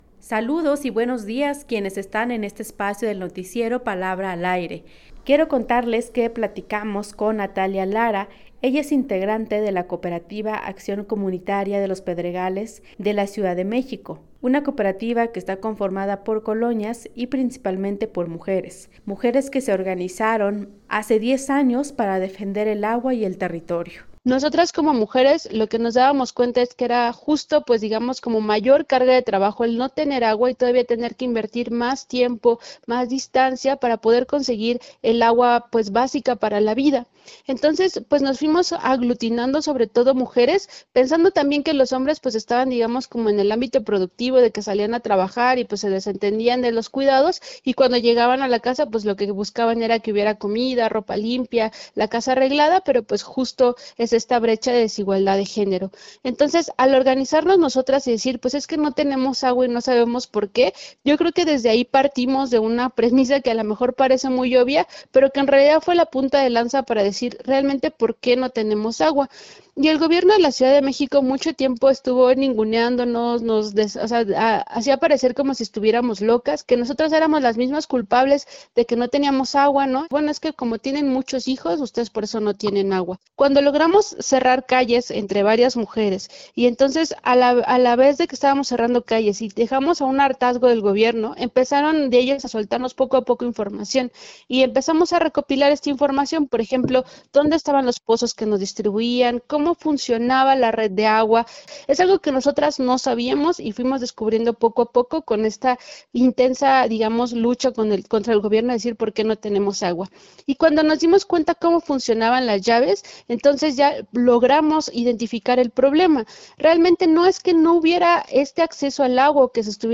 Les llevo 10 años para poder llegar a acuerdos con las autoridades y que hubiera una repartición del agua más equitativa. La entrevista